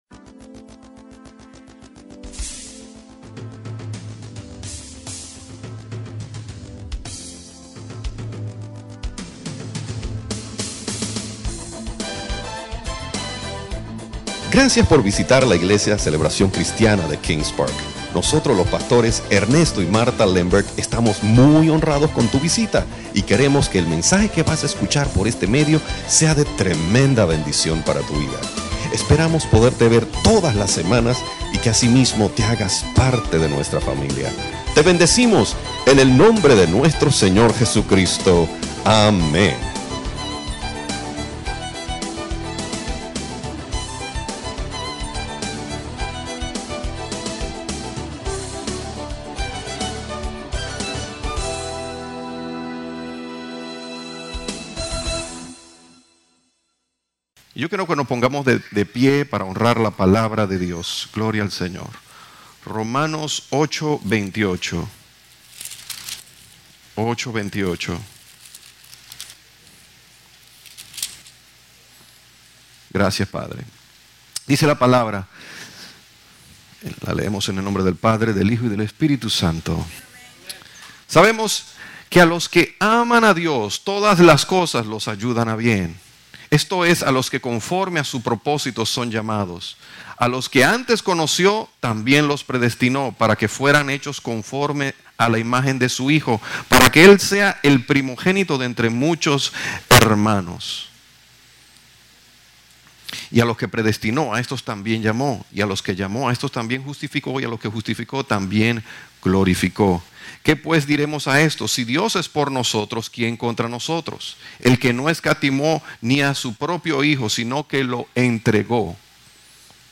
Series: Servicio Dominical